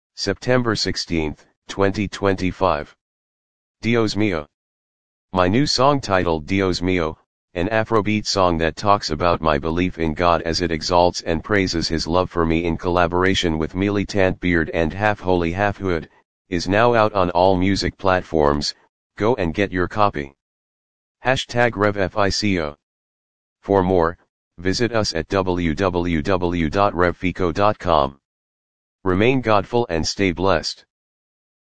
an Afrobeat song